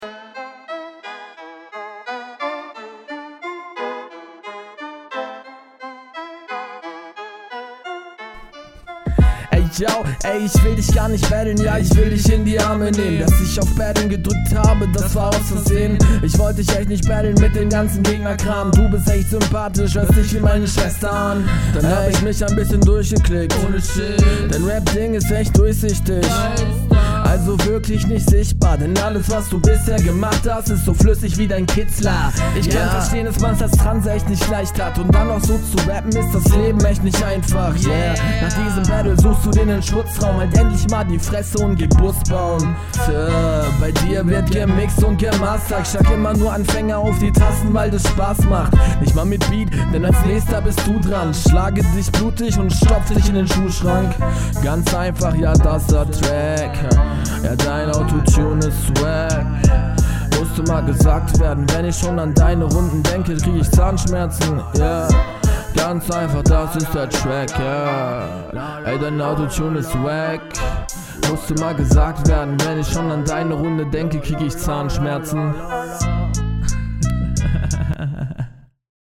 Mega geile Stimme und man versteht auch so ziemlich alles …
gefällt mir besser kommst lässiger und routinierter auf den beat das Gesamtpaket meiner Meinung einfach …